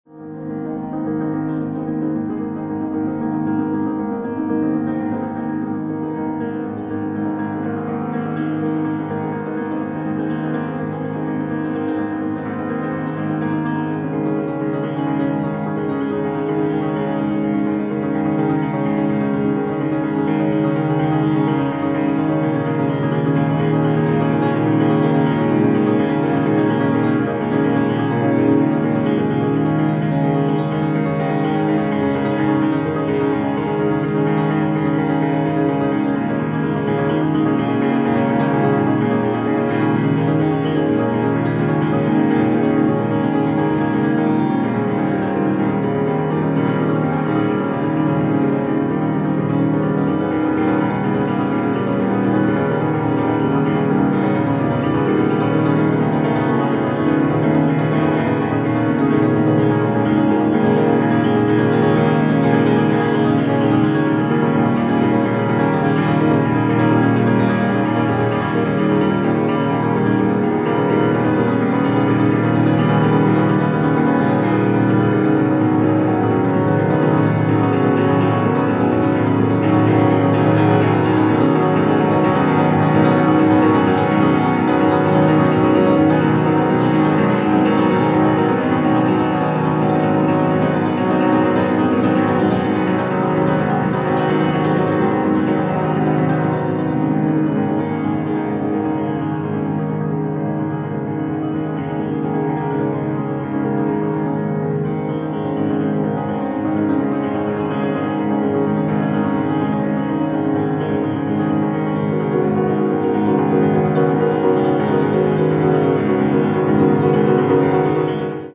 for Dutch pianist
piano